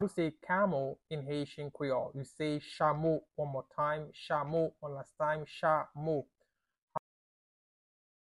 Pronunciation:
How-to-say-Camel-in-Haitian-Creole-Chamo-pronunciation-by-a-Haitiian-teacher.mp3